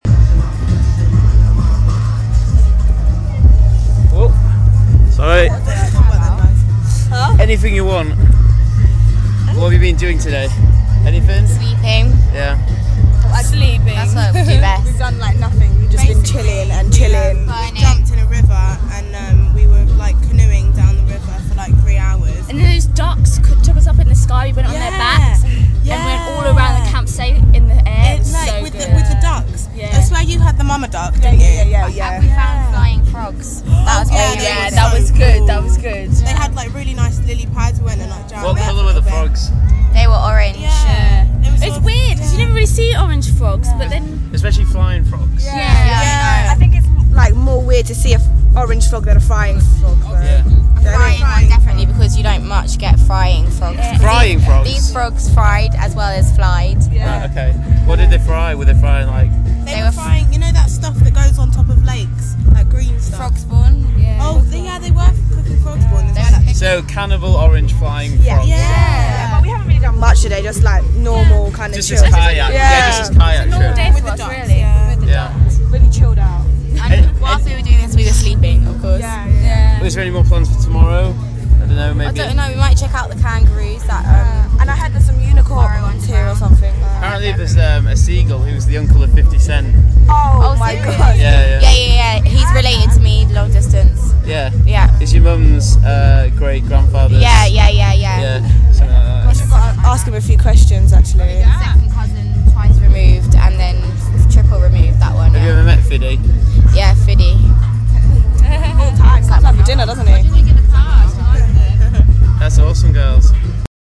Frogs
53312-frogs.mp3